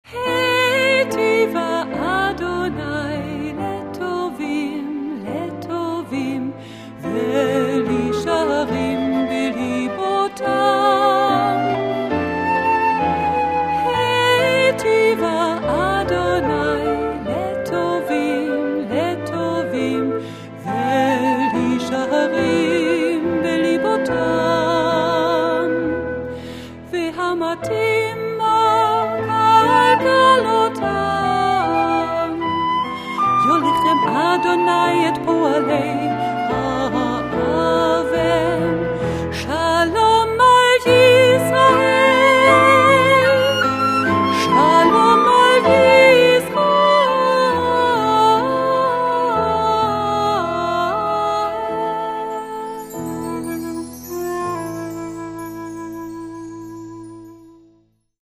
Querflöte, Altquerflöte